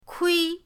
kui1.mp3